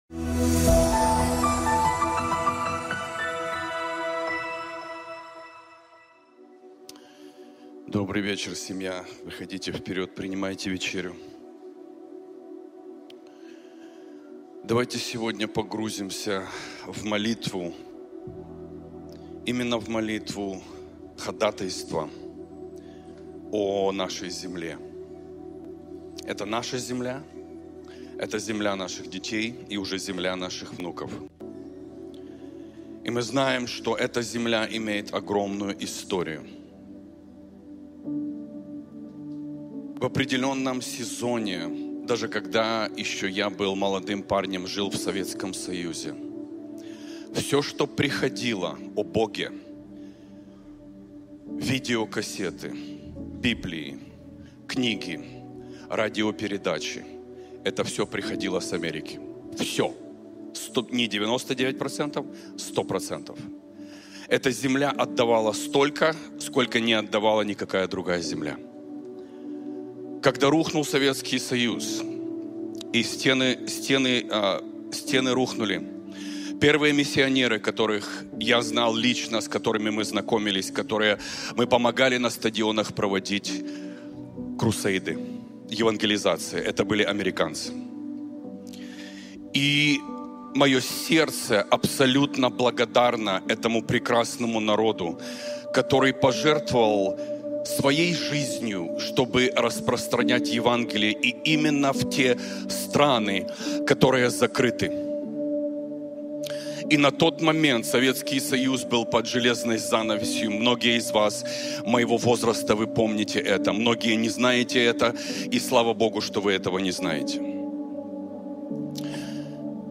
«Крест» (Пер. с англ.)